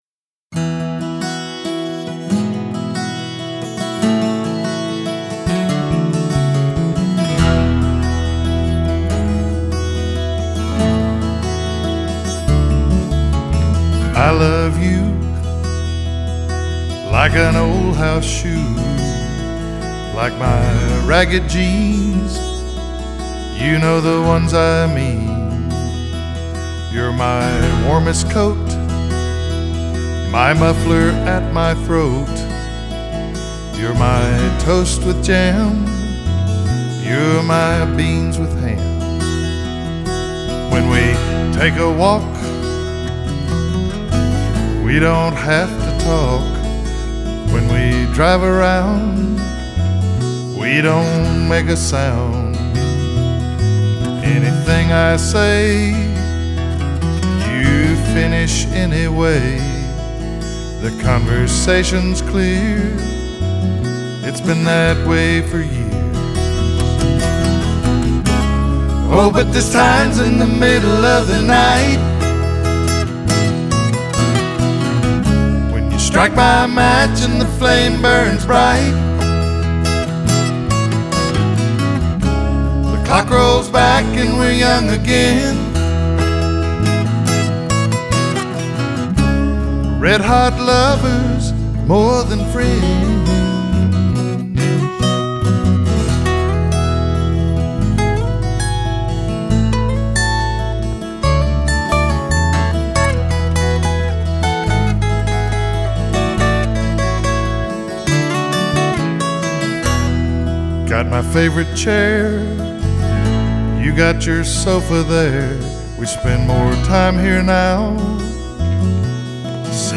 studio all originals